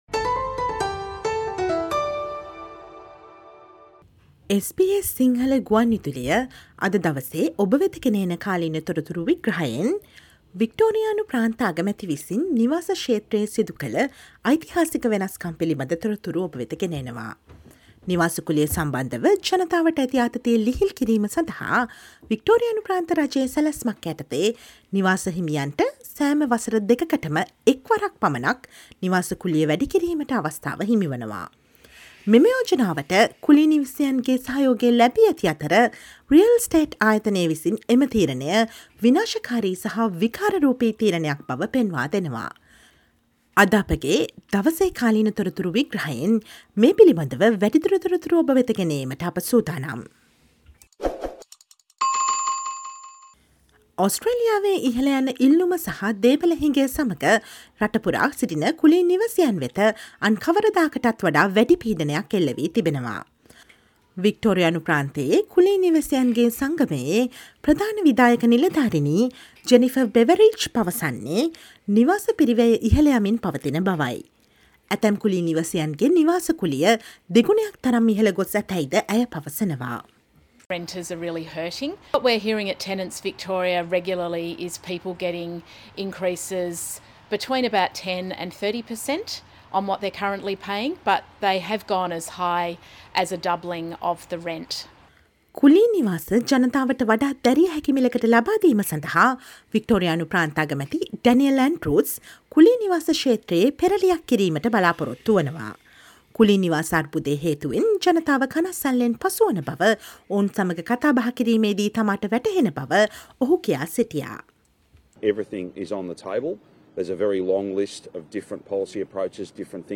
Listen to the SBS Sinhala radio explainer on the historical decision in rental market to solve the housing crisis in Victoria but leads to chaoes which can make it worse.